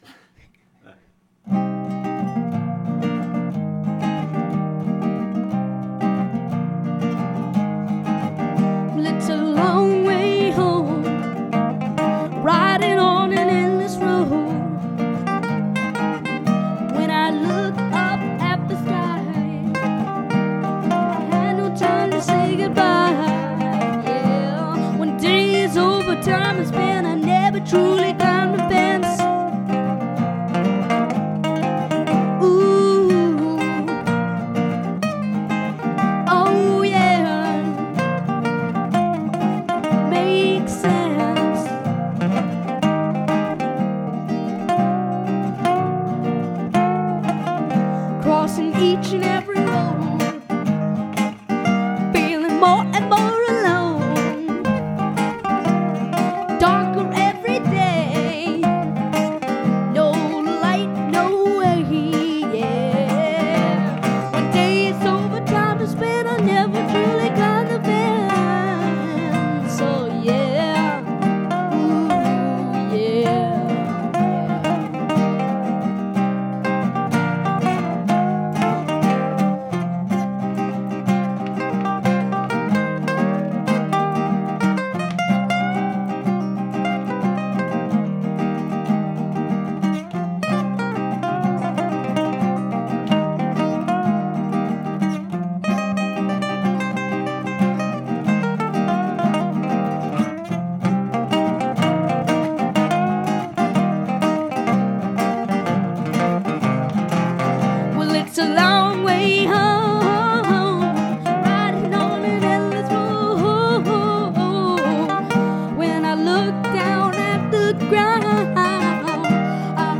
composer and guitarist
guitar solos